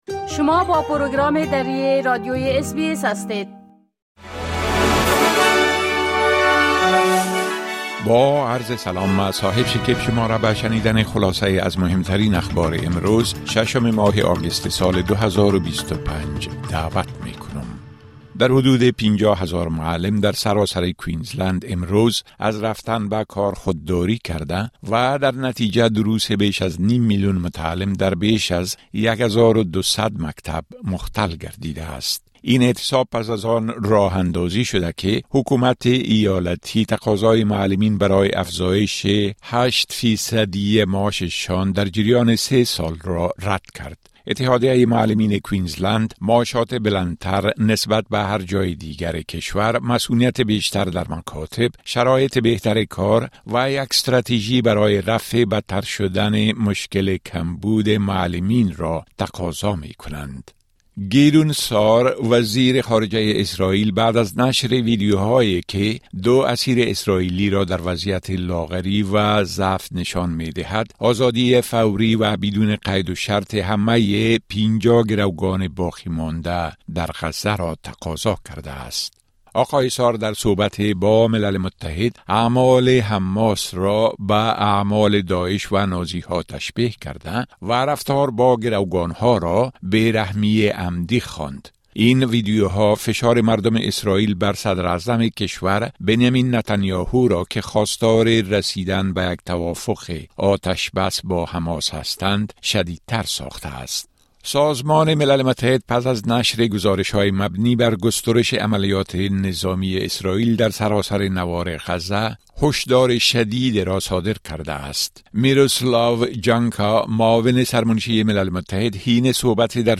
خلاصۀ مهمترين خبرهای روز از بخش درى راديوى اس‌بى‌اس